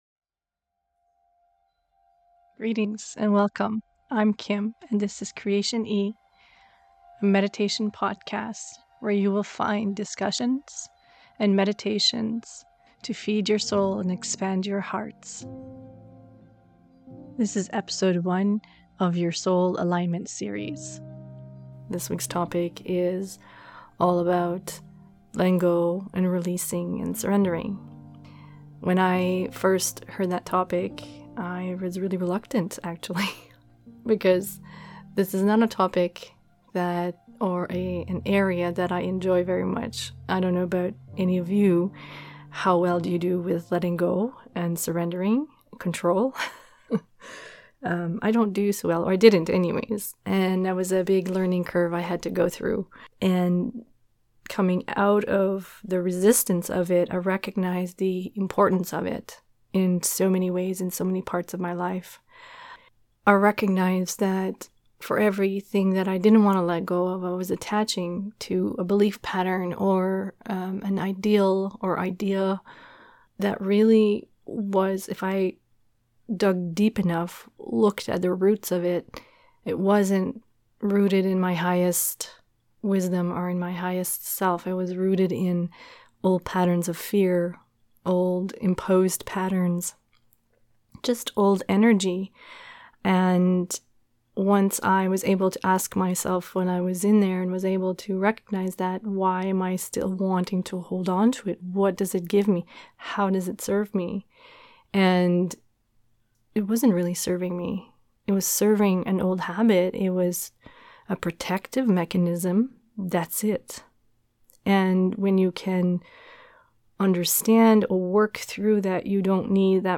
In this Episode, I discuss the importance of getting better acquainted with Letting Go and releasing everything that no longer serves you in your life. It's followed by a 15 min breathing meditation that focuses being in a surrendered state.